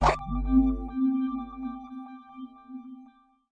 Boot Controller Screen Sound Effect
boot-controller-screen-1.mp3